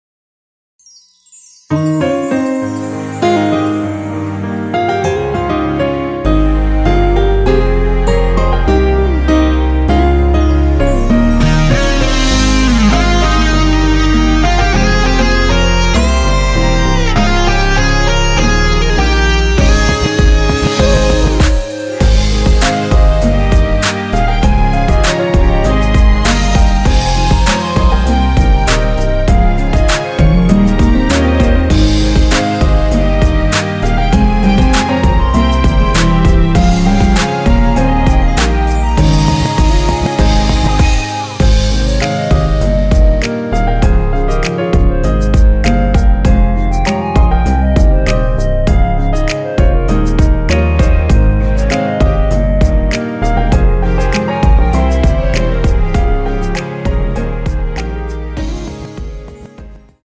(-3)내린 멜로디 포함된 MR 입니다.(미리듣기 참조)
Ab
앞부분30초, 뒷부분30초씩 편집해서 올려 드리고 있습니다.
중간에 음이 끈어지고 다시 나오는 이유는